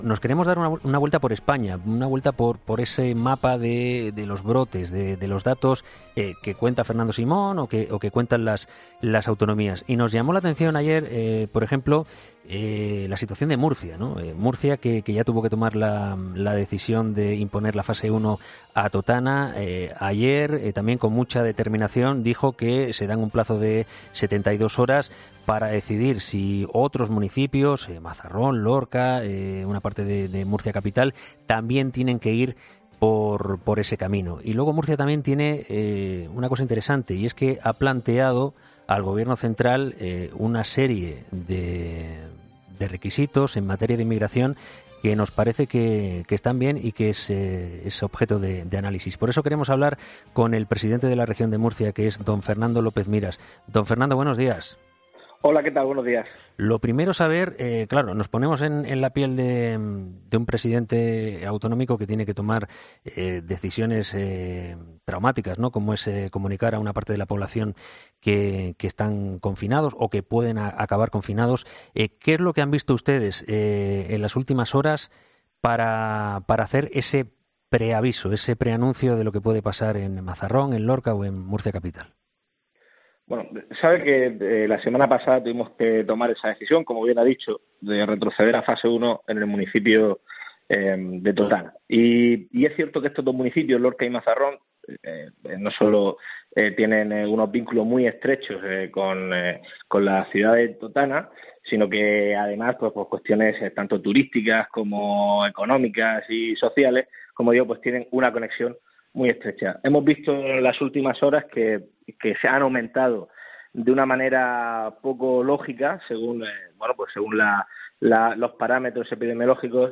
Fernando López Miras, presidente de la Región de Murcia, ha sido entrevistado este martes en 'Herrera en COPE', después de que el Gobierno se haya dado un plazo de 72 horas para decidir si aplica medidas de confinamiento similares a las establecidas en Totana a los municipios de Lorca y Mazarrón, así como al de Murcia, donde hay un importante incremento de los casos de coronavirus vinculados al ocio nocturno.